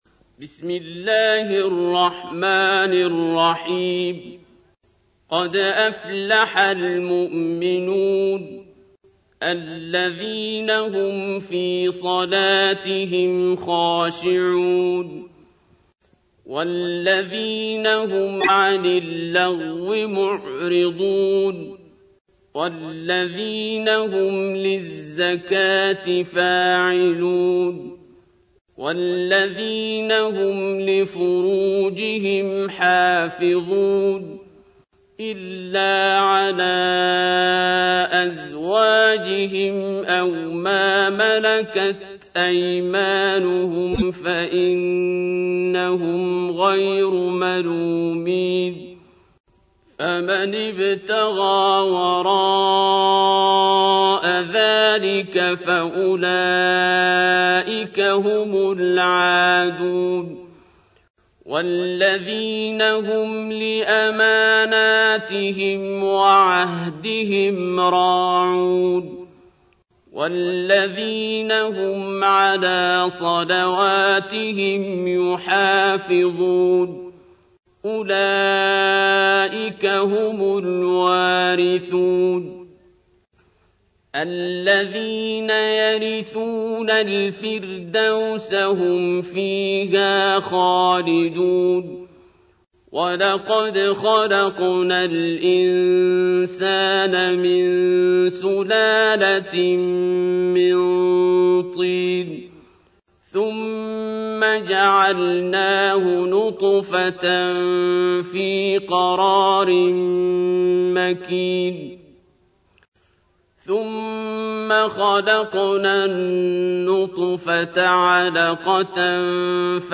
تحميل : 23. سورة المؤمنون / القارئ عبد الباسط عبد الصمد / القرآن الكريم / موقع يا حسين